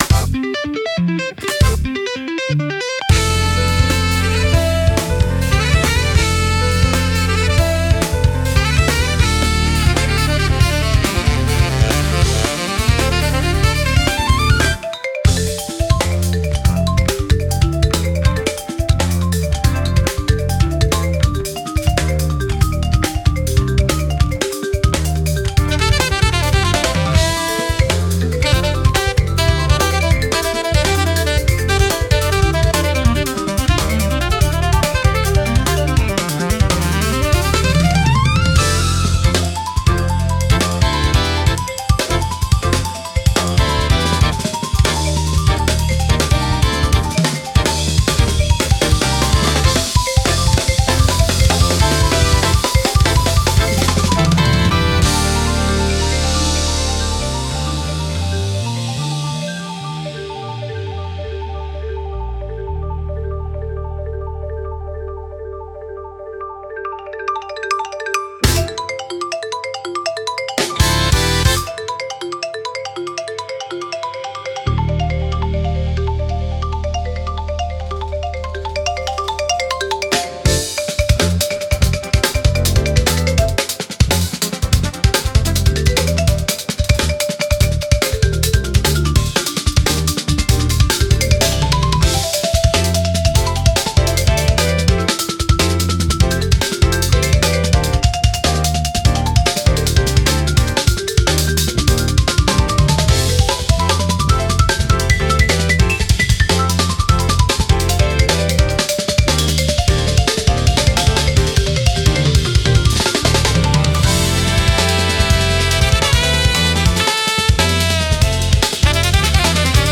The frequency range was sligtly extended (about 1000Hz).